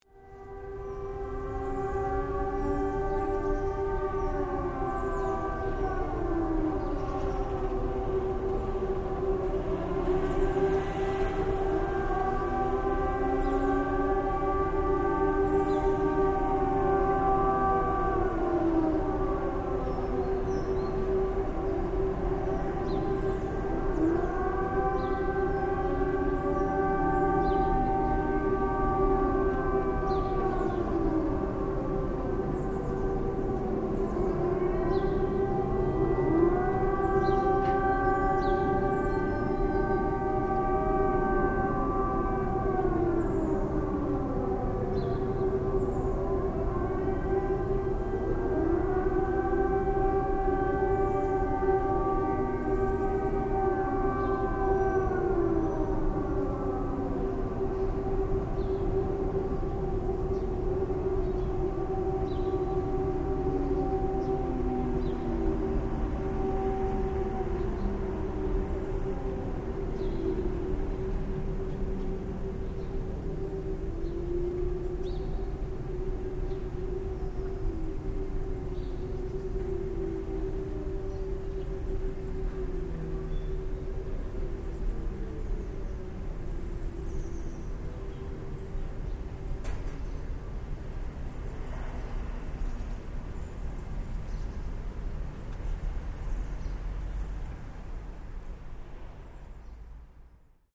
Monthly test of national alarm siren, Paris
At midday on the first Wednesday of every month, each village, town and city in France tests the national emergency alarm siren. This was recorded in the 11th district of Paris.